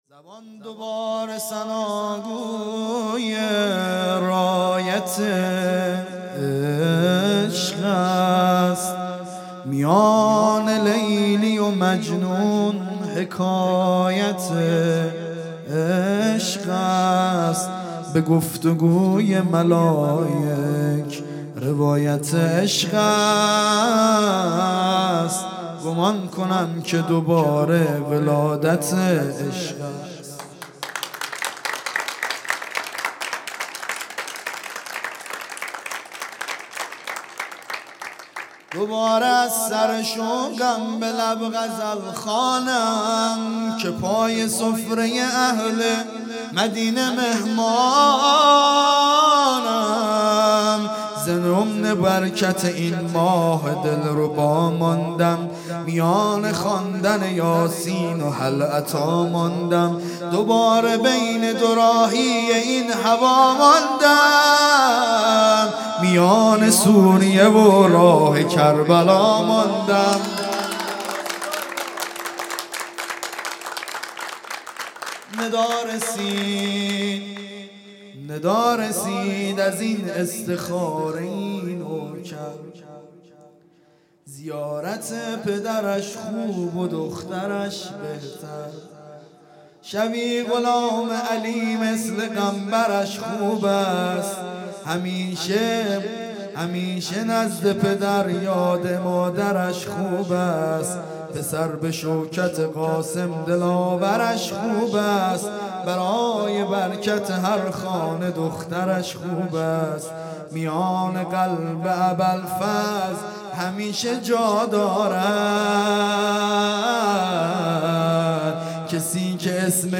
ولادت حضرت رقیه (س) | ۲۶ اردیبهشت ۹۶